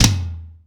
ROOM TOM3B.wav